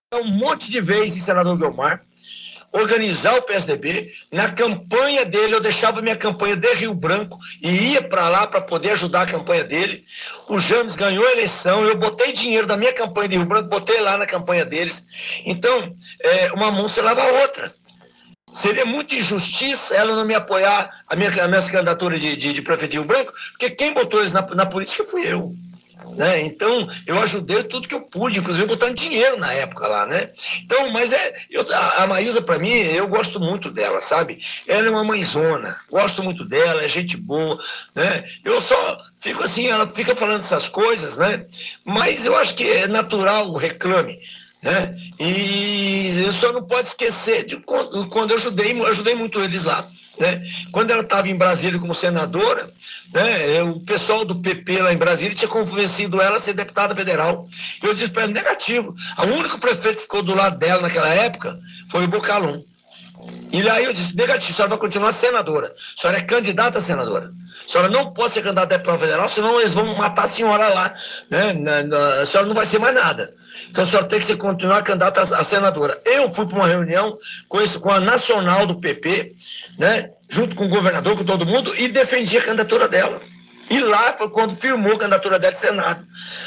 OUÇA O ÁUDIO DO PREFEITO ABAIXO: